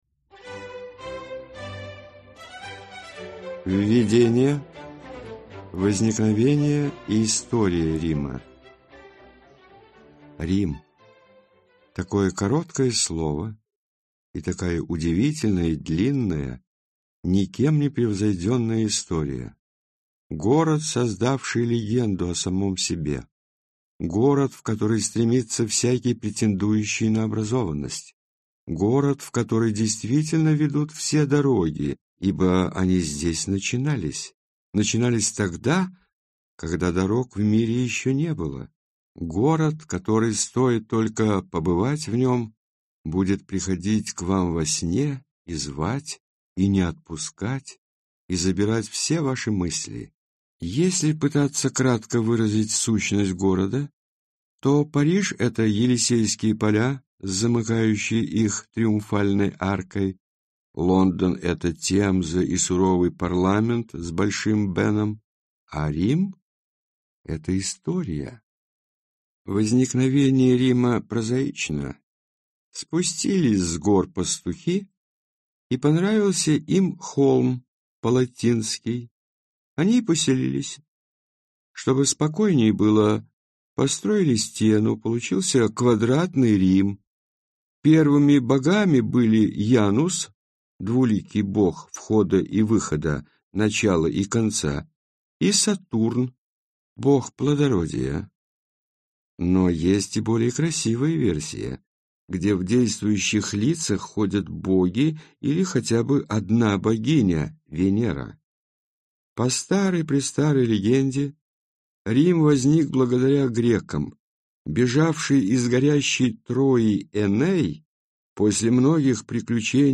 Аудиокнига Рим. Путеводитель | Библиотека аудиокниг